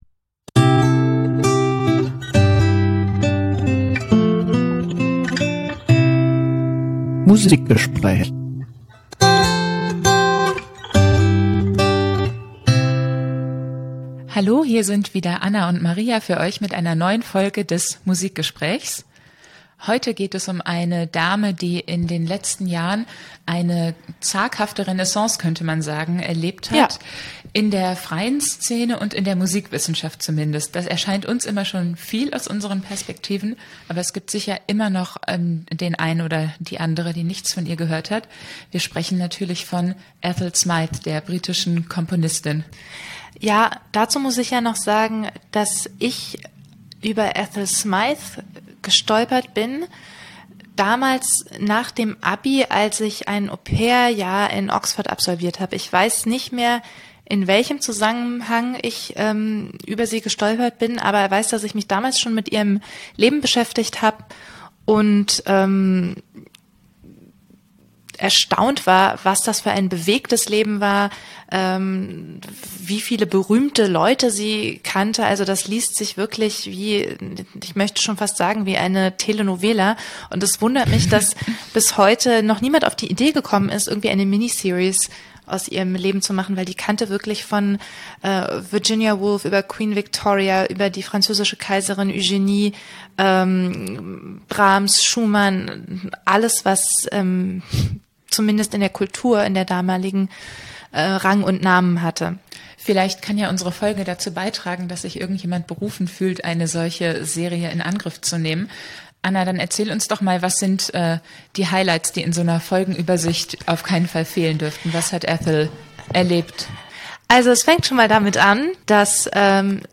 Ethel Smyth, Hedda Wagner & Johanna Kinkel ~ Musikgespräch (AAC Feed) Podcast